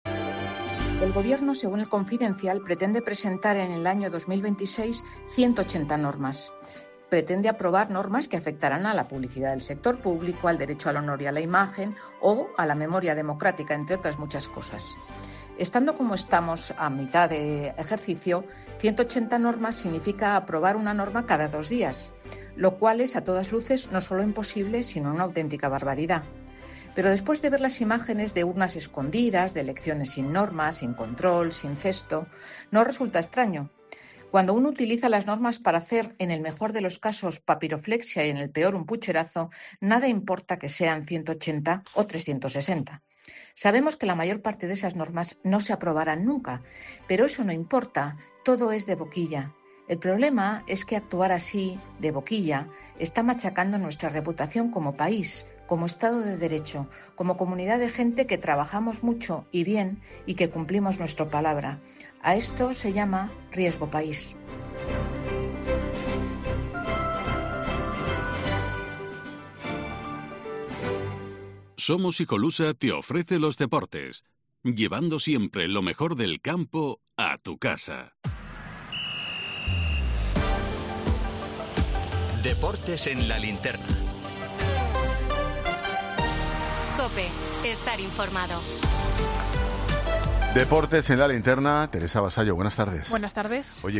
Reyes Calderón da el apunte en Femenino Singular en La Linterna con Ángel Expósito sobre el plan del Gobierno de 180 normas